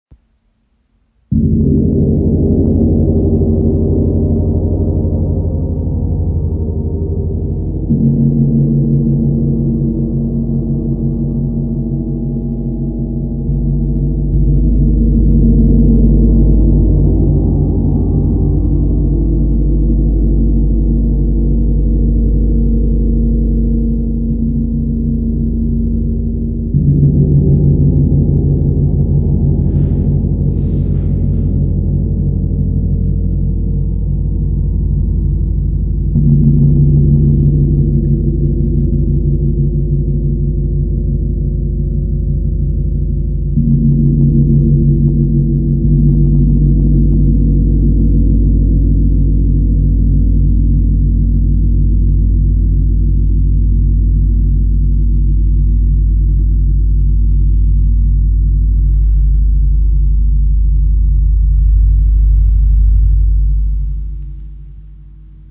Klangbeispiel
Sein Klang ist tief, ausgewogen und reich an Obertönen, die eine Atmosphäre zwischen Erdentiefe und kosmischer Weite erschaffen. Mit Reibungsschlägeln wie den B Love Flumies entstehen sanfte, traumhafte Wal- und Delfinsounds.
WOM KI Gong – Der Klang der inneren Weite | ø 110 cm | Edelstahl-Gong Wie aus der Tiefe des Meeres geboren, singt dieser Gong in Tönen von Walgesang und innerer Weite.